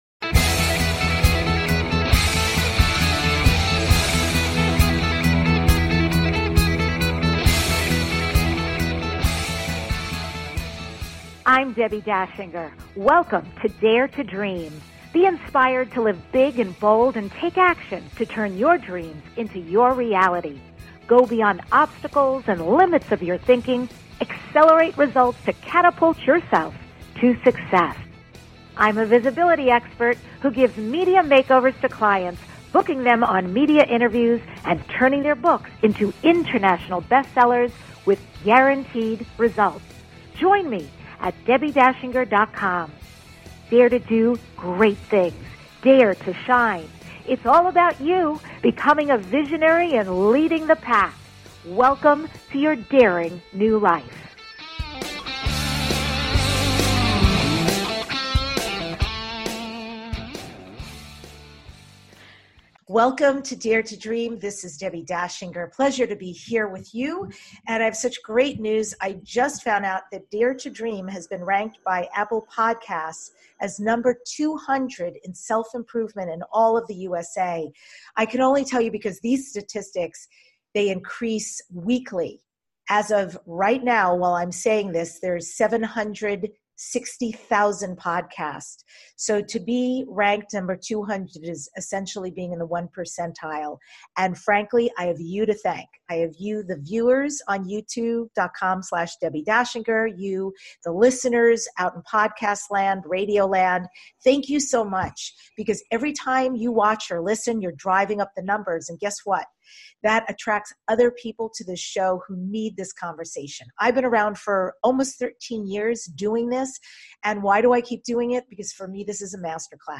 Guest, James Redfield
The award-winning DARE TO DREAM Podcast is your #1 transformation conversation.